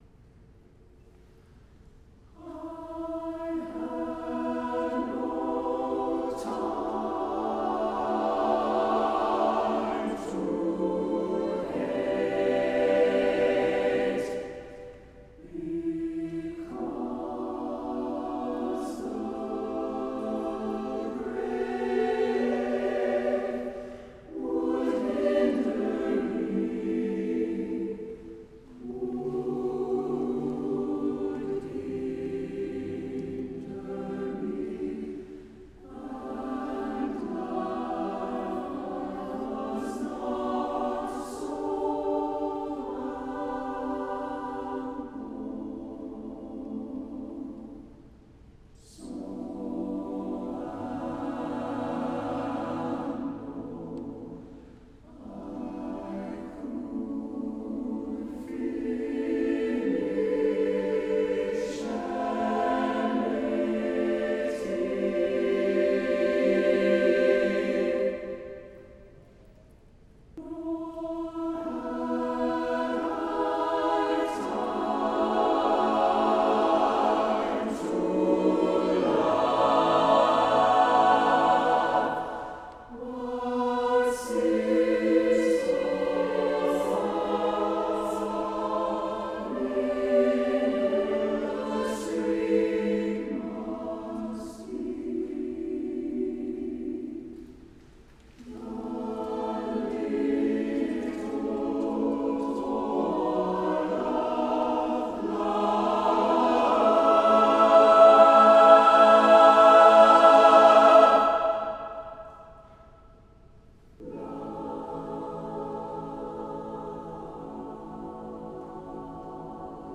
SATB a cappella Chorus with Divisi